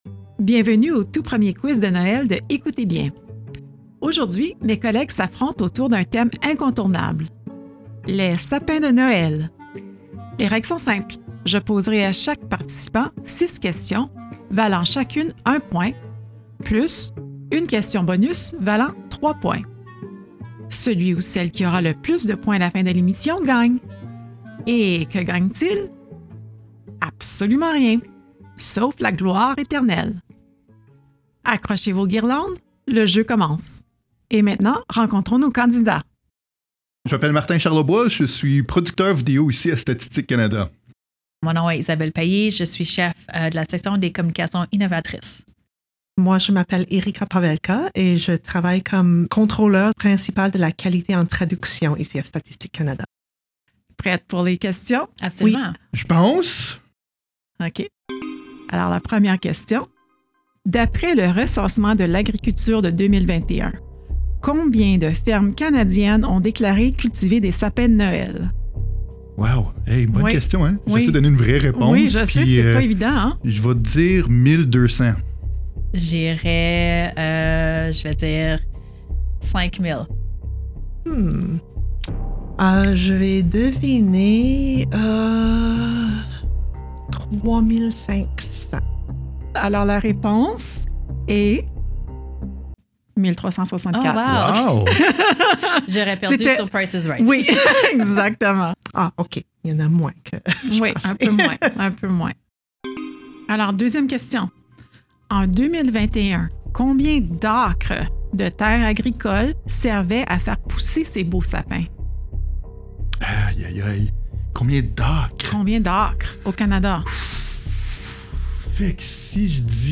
Dans cet épisode spécial de Hé-coutez bien!, nos collègues s’affrontent dans un quiz consacré à l’industrie canadienne du sapin de Noël.
Animatrice
Invités